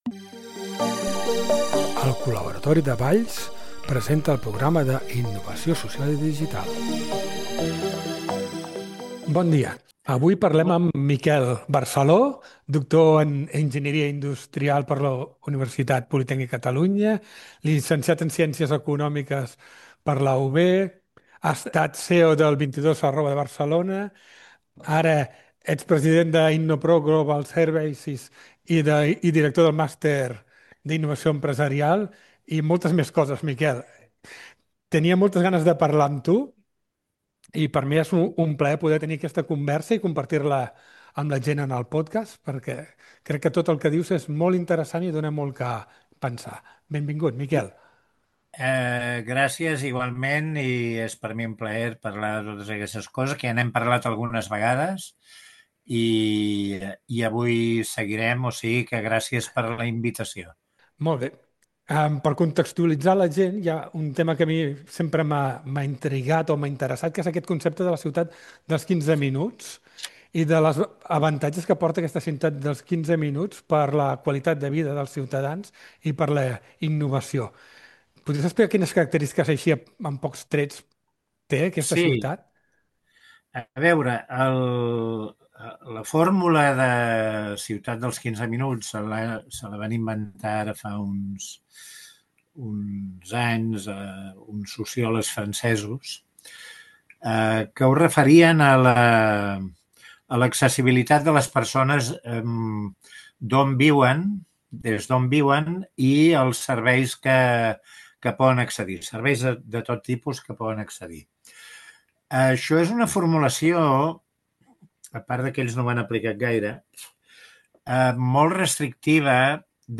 En aquesta entrevista